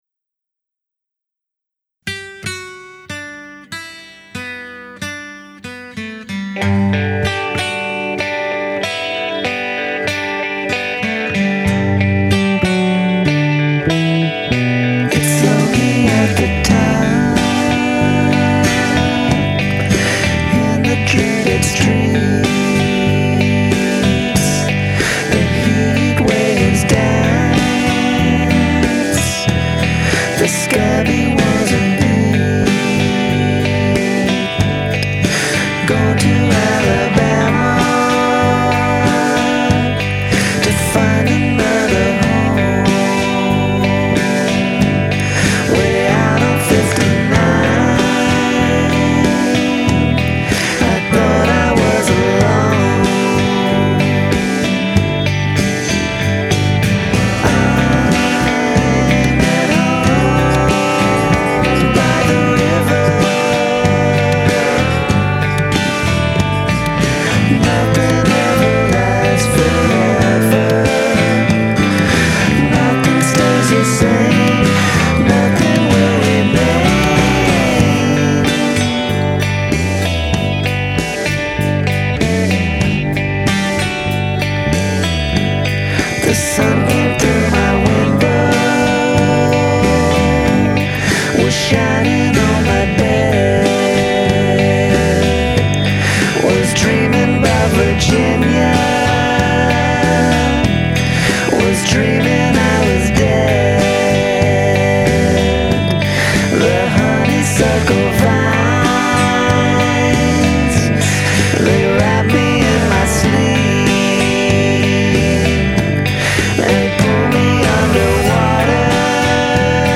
I recorded this at home on an 8-track cassette.
a sweet, slow, dreamy number